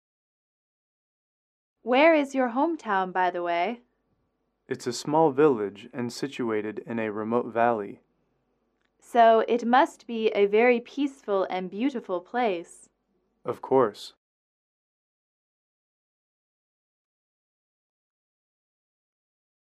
英语口语情景短对话28-4：家乡（MP3）